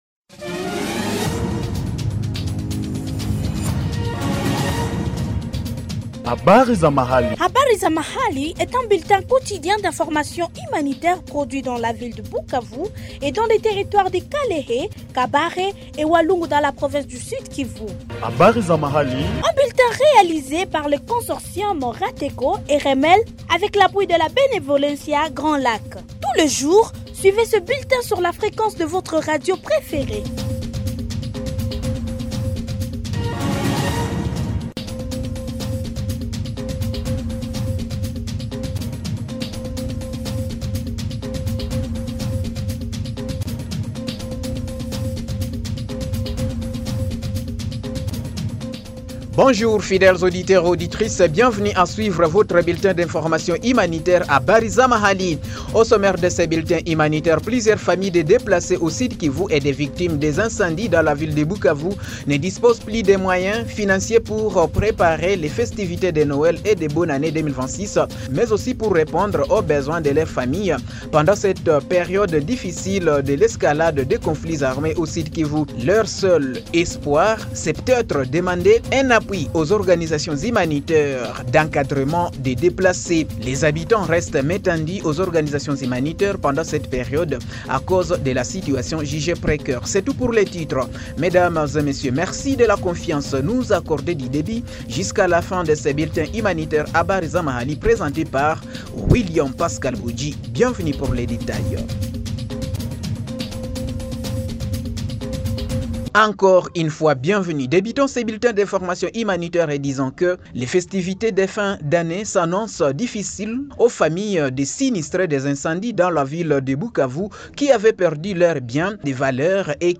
Suivez ici le bulletin Habari za Mahali du 09 décembre 2025 produit au Sud-Kivu